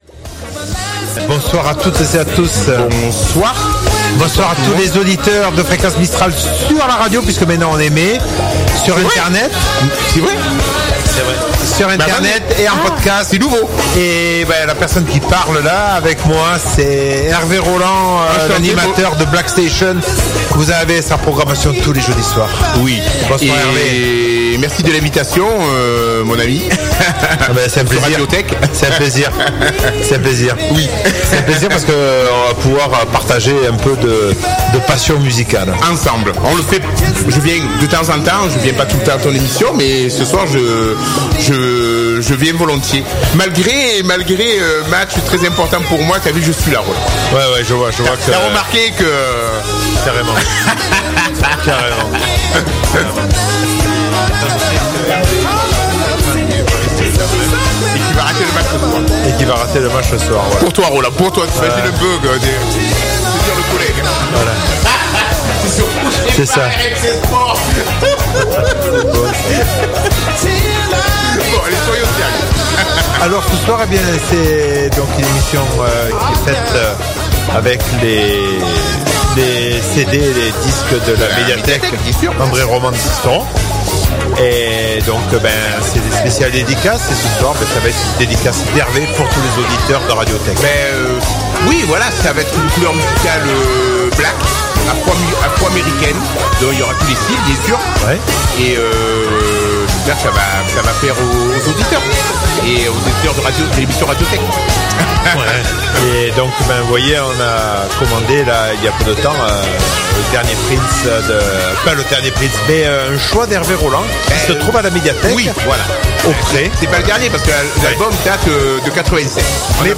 Soul, Funk et plus !